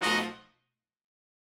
GS_HornStab-F7b2b5.wav